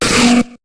Cri d'Ymphect dans Pokémon HOME.